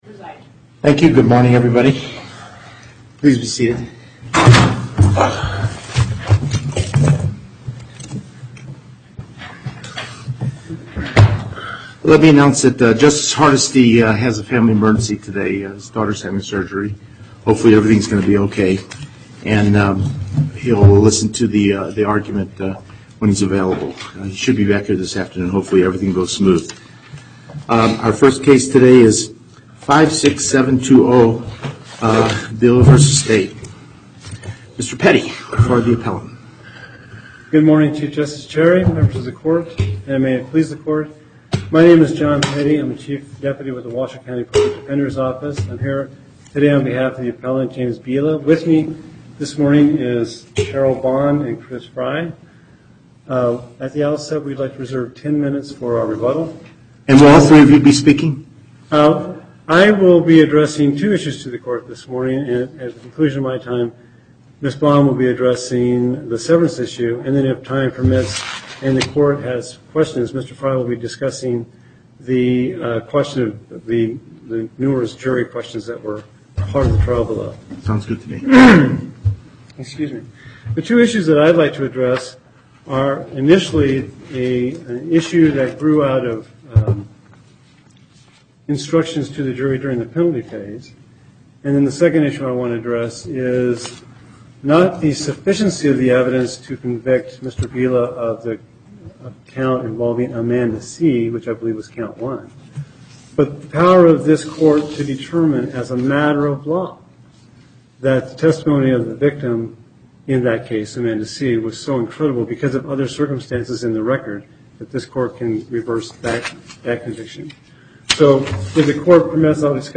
Location: Carson City En Banc Court, Chief Justice Cherry Presiding